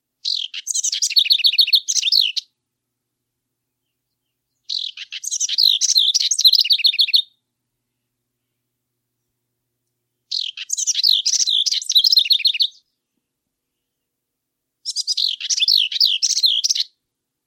House Wren - Song
Rapid bubbling chatter, higher in the middle.